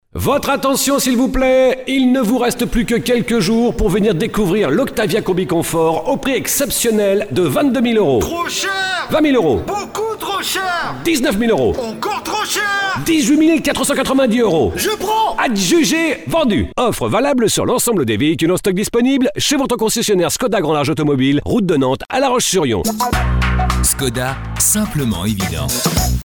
Nous pouvons créer pour vous les textes de vos spots publicitaires et réalisé ensuite l’enregistrement et le montage sur musique.